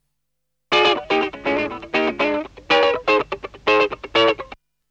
funky guitar Meme Sound Effect
funky guitar.mp3